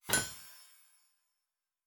beyond/Assets/Sounds/Fantasy Interface Sounds/Blacksmith 04.wav at main
Blacksmith 04.wav